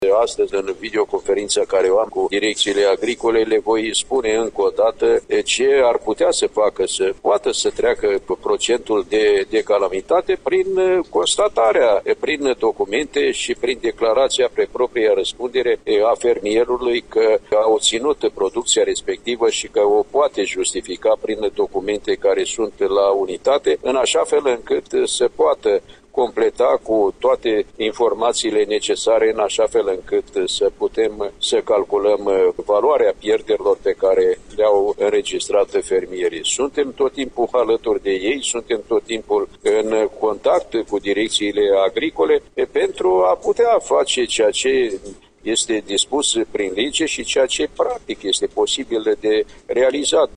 Într-o intervenție în exclusivitate pentru Radio România Iași, el a precizat că Direcțiile Agricole Județene vor lua în calcul inclusiv acele procese verbale în care nu se regăsește procentul de calamitate: De astăzi, în videoconferința pe care o am cu Direcțiile Agricole, le voi spune încă o dată, ce ar putea facă poată treacă de procentul de calamitate prin constatare, prin documente și prin declarația pe propria răspundere a fermierului a obținut producția respectivă și o poate justifica prin documente care sunt la unitate, în așa fel încât poată completa cu toate informațiile necesare, în așa fel încât, putem calculăm valoarea pierderilor pe care le-au înregistrat fermierii.